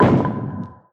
pin_hitted.mp3